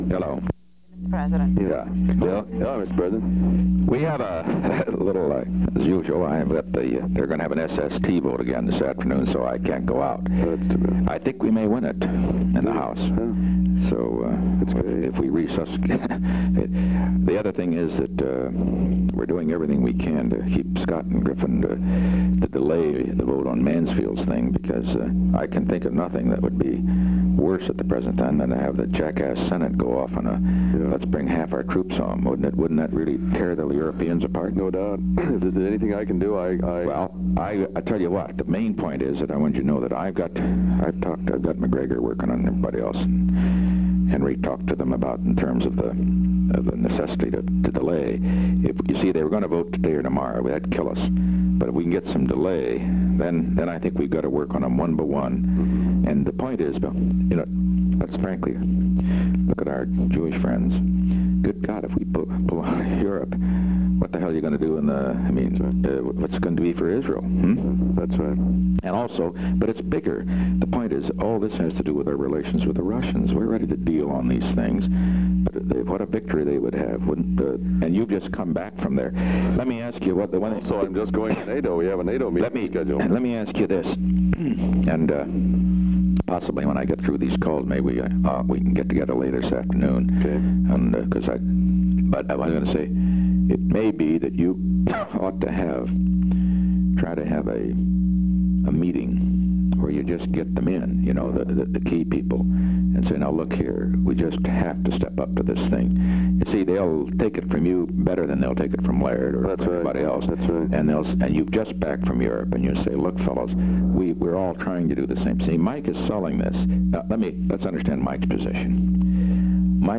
Conversation No. 3-9
Location: White House Telephone
The President talked with William P. Rogers.